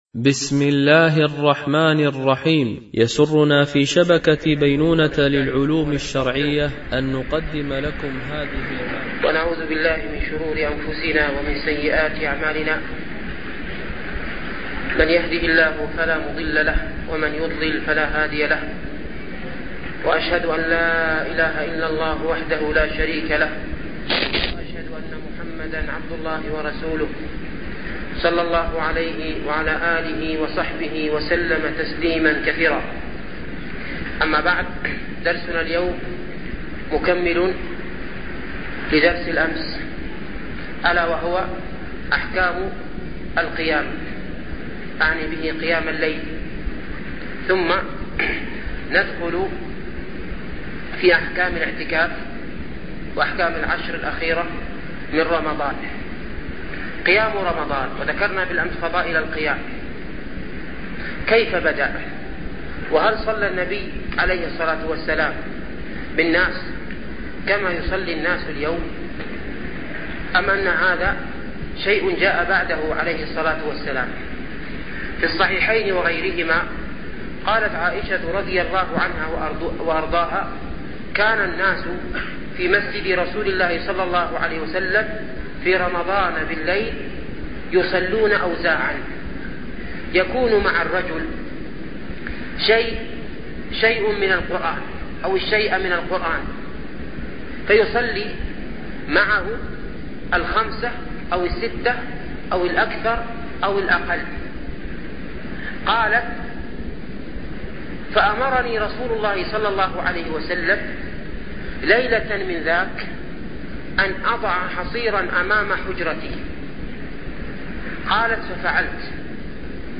دروس رمضانية ـ الدرس الثالث عشر